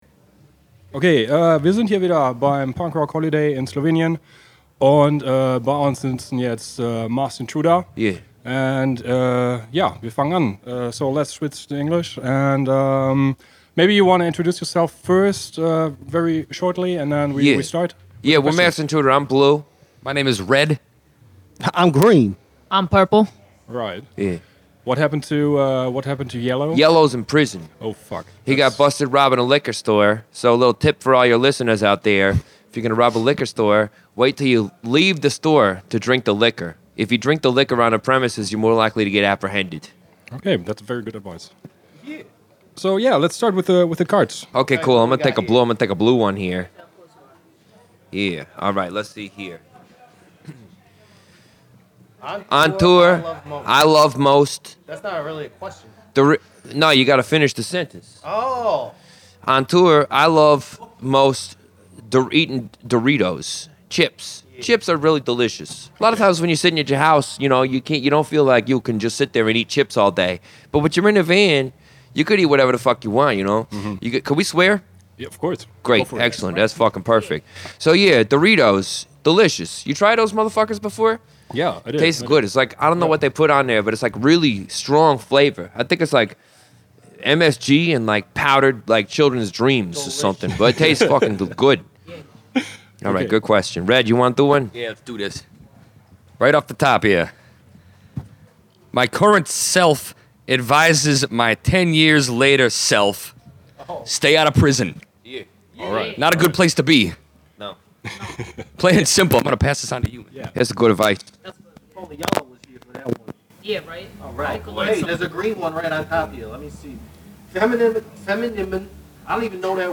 Letzte Episode Masked Intruder – Interview @ Punk Rock Holiday 1.9 20. August 2019 Nächste Episode download Beschreibung Teilen Abonnieren Masked Intruder sneaked in for a short interview before their show at the Punk Rock Holiday.
masked-intruder-interview-punk-rock-holiday-1-9-mmp.mp3